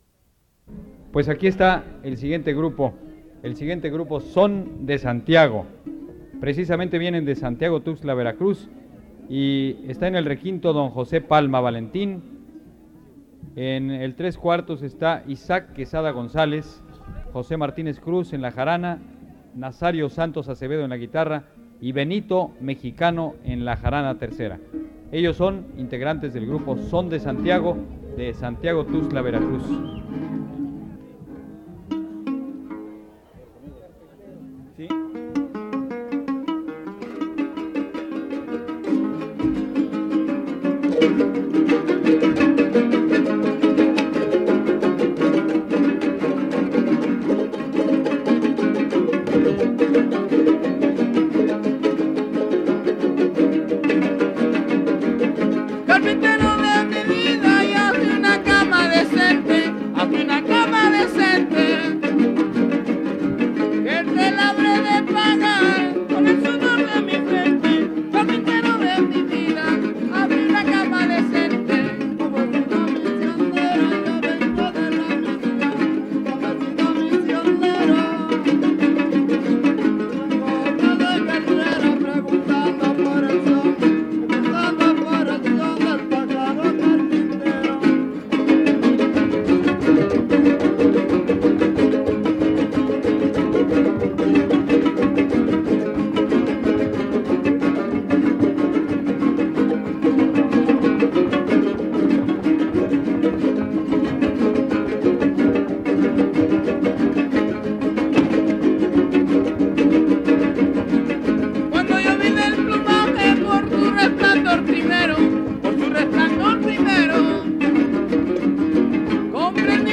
• Son de Santiago (Grupo musical)
Noveno Encuentro de jaraneros